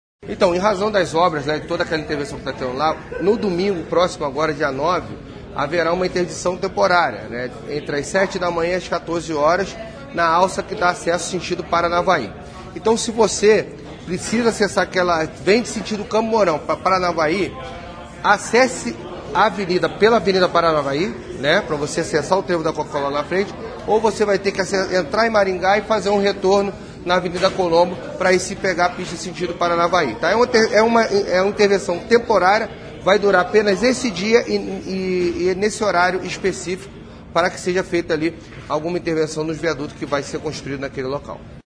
Ouça o que disse o secretário da Semob, Luiz Alves.